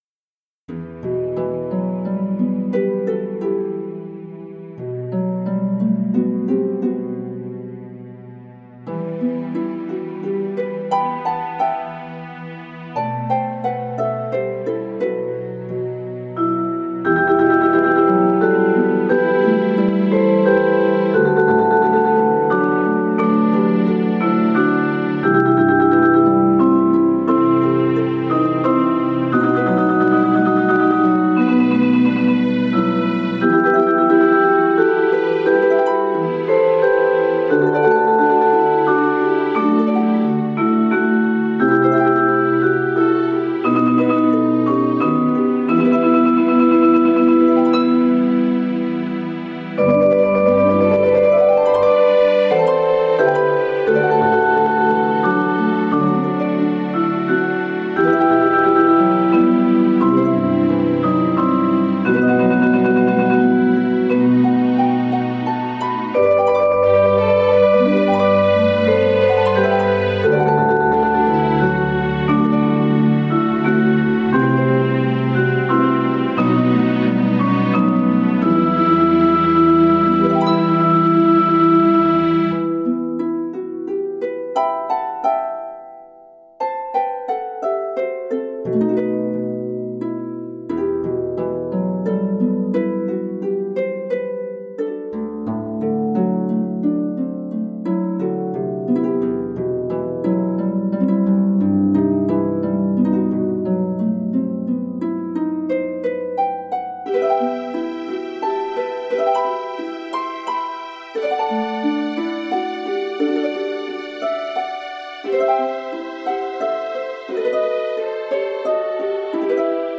This year I added a new project to the mix by restarting the hand bell choir I used to direct when we first arrived in Idaho.
So….., as my gift to you this blessed Christmas season I have uploaded several of the pieces we performed for your listening pleasure.
what-child-is-this-sound-track-with-hand-bells-2.mp3